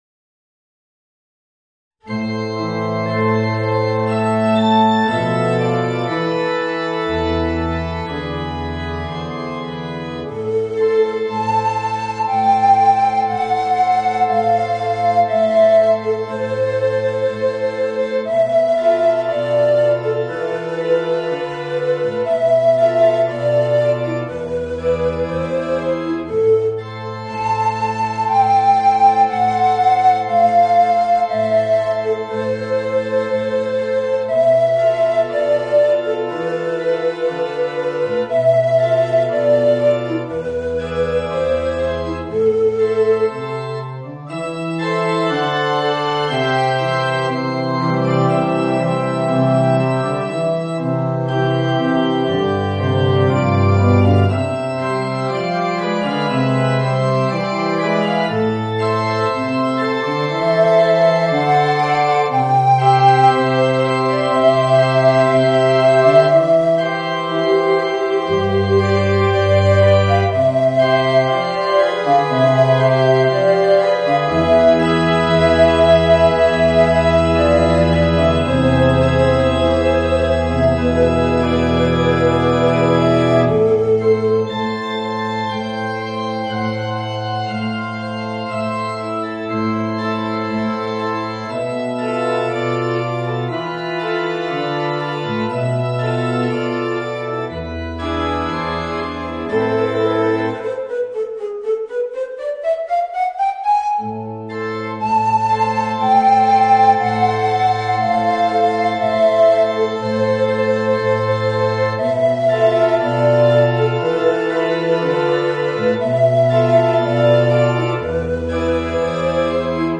Voicing: Tenor Recorder and Piano